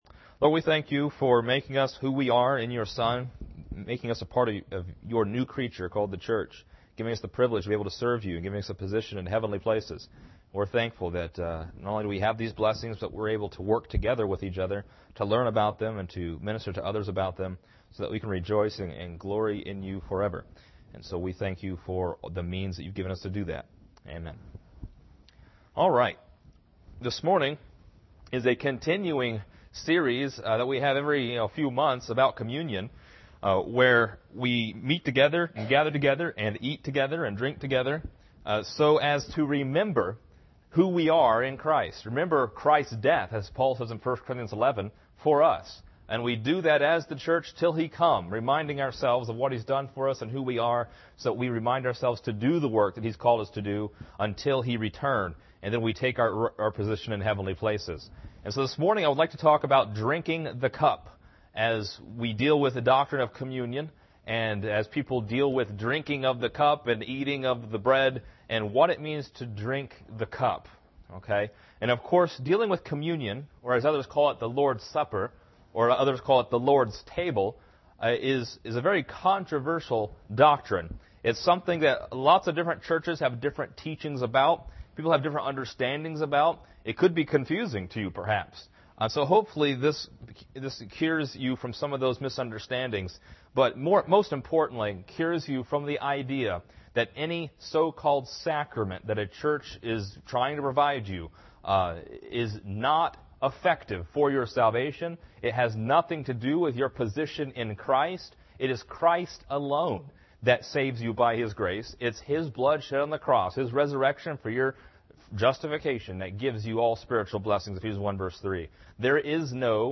A lesson on the distinctly Pauline doctrine of communion found in 1 Corinthians. Learn what it means to drink of the cup, what’s in the cup, and how to be partaker’s in the Lord’s Table.